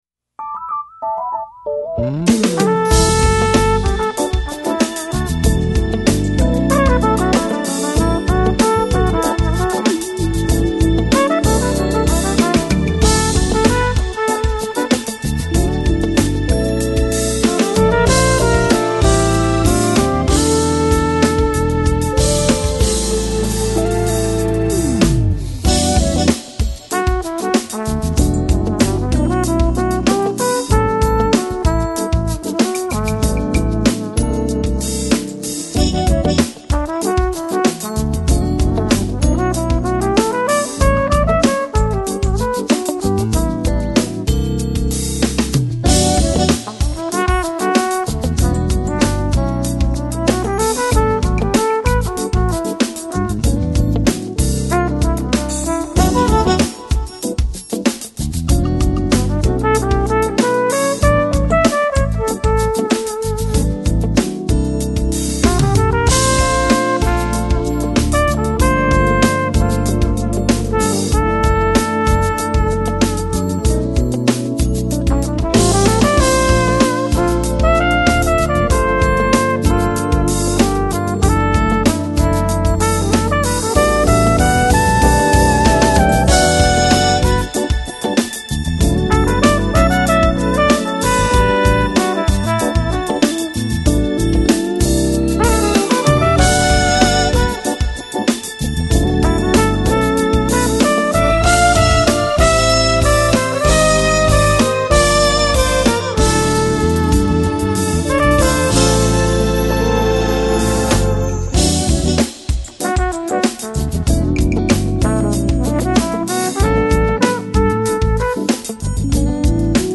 Smooth Jazz Издание